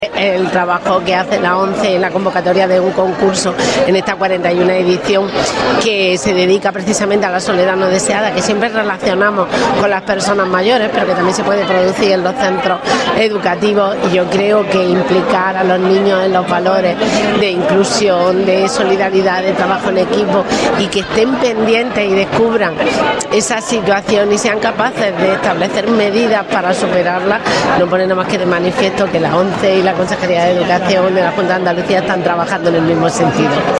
Los premiados también ensayaron en clase un himno, que cantaron a viva voz todos los presentes para constatar su compromiso con la causa
Audio con el himno
El ambiente en el salón de actos del CRE fue de celebración